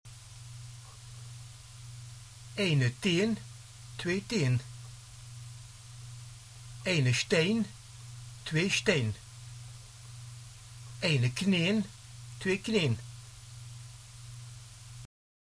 hetlimburgs2.mp3